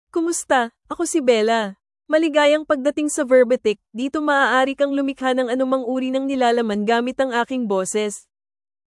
BellaFemale Filipino AI voice
Bella is a female AI voice for Filipino (Philippines).
Voice sample
Listen to Bella's female Filipino voice.
Female